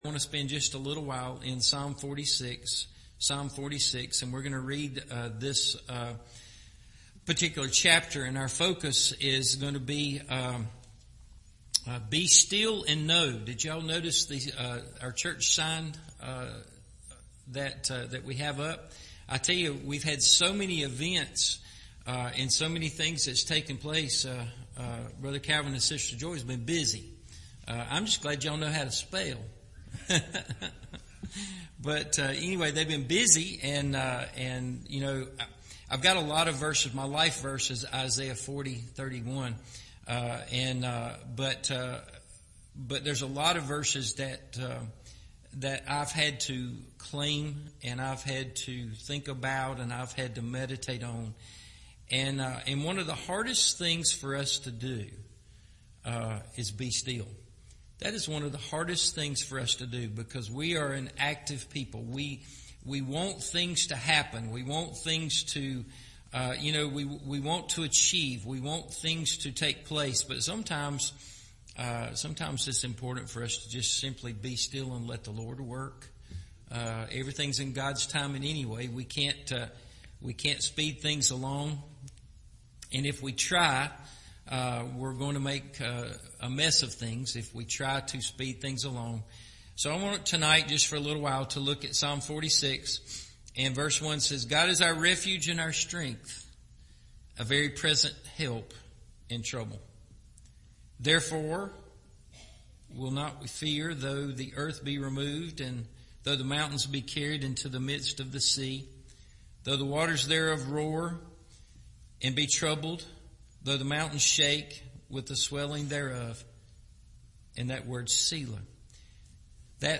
Be Still and Know – Evening Service